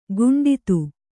♪ guṇḍitu